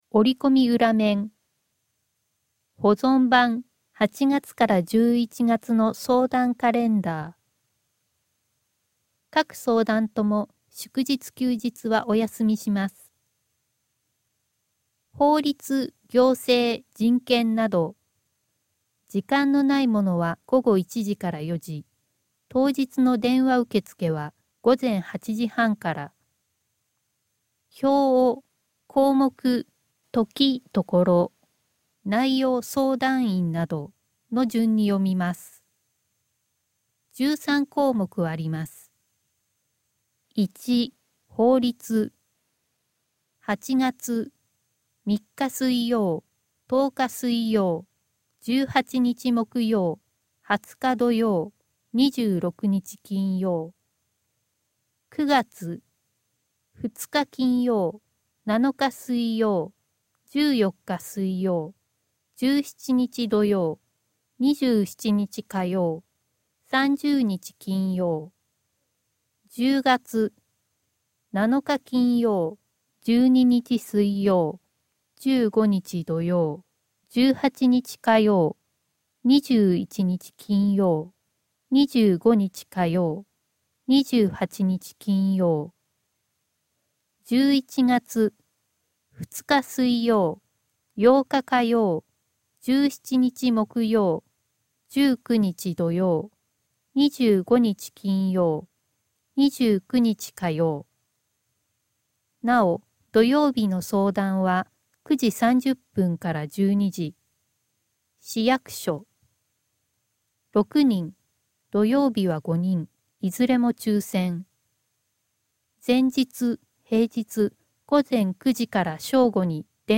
声の広報（概要版）